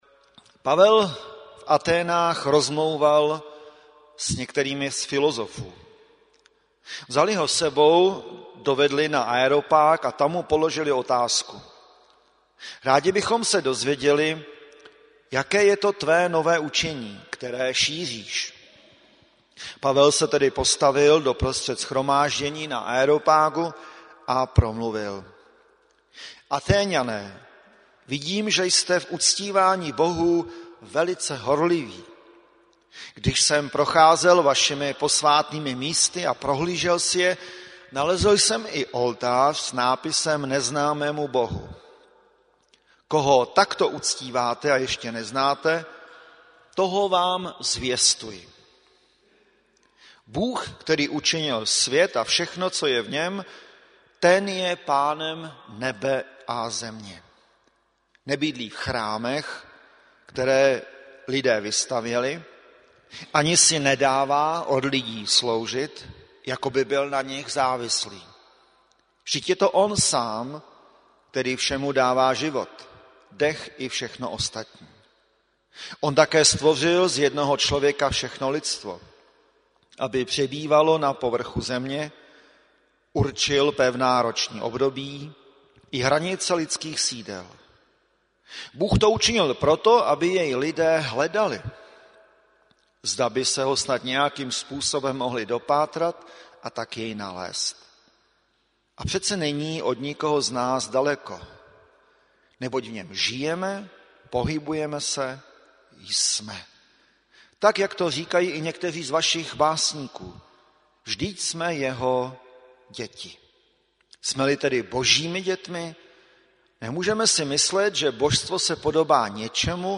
Bohoslužby - Neděle Cantate